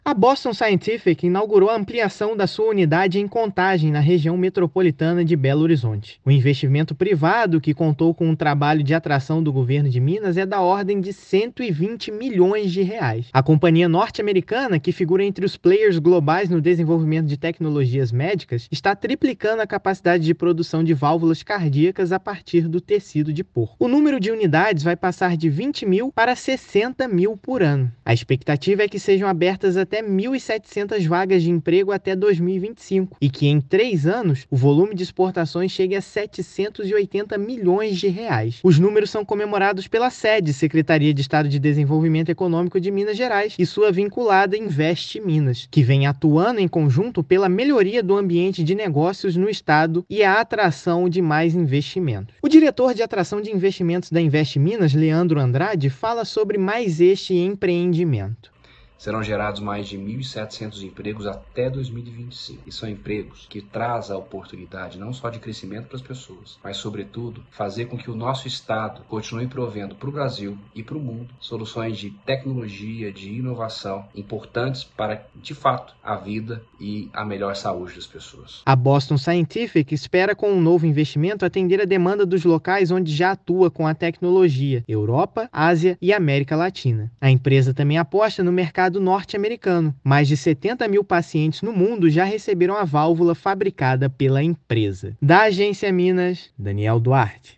Investimento da Boston Scientific em Contagem, oficializado na terça-feira (7/5), vai gerar 1,7 mil postos de trabalho no estado até 2025. Ouça matéria de rádio.
Inauguração_Boston_Scientific.mp3